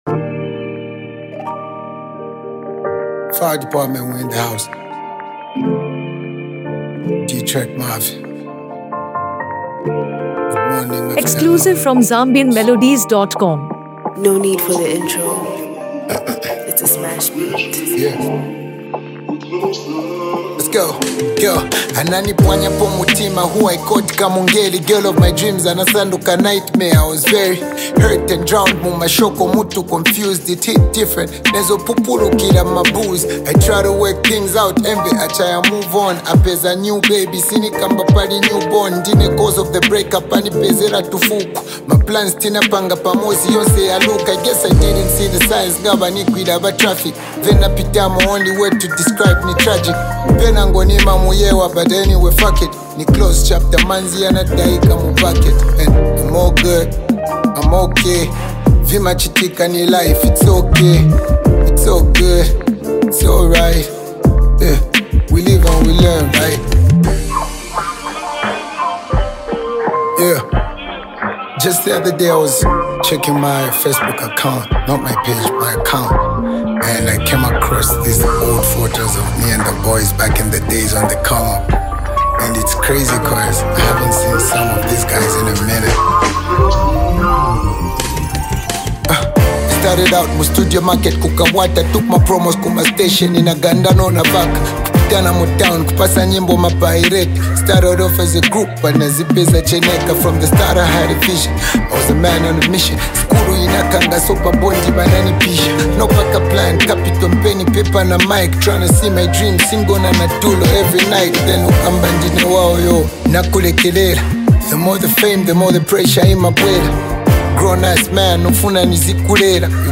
Uplifting Zambian Anthem
Motivational Hip-Hop Zambia
Genre: Inspirational/Afro-beats